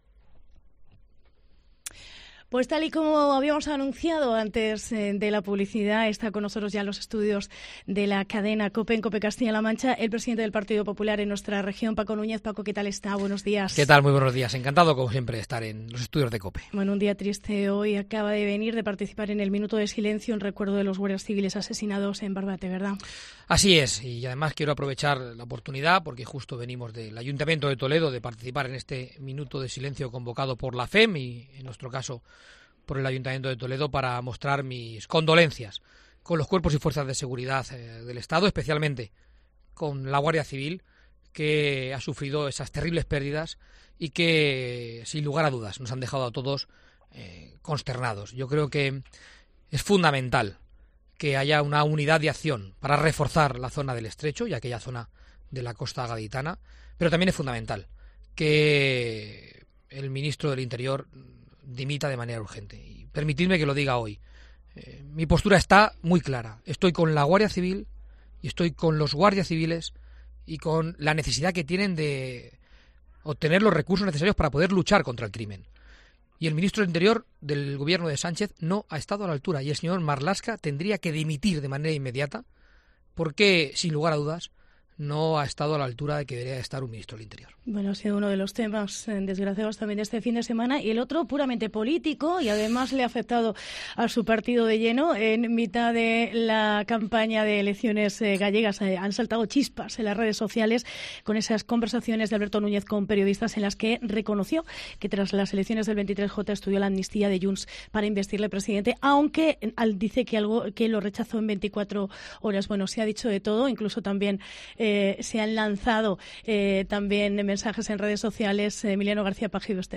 El presidente del Partido Popular regional, Paco Núñez visita COPE Castilla-La Mancha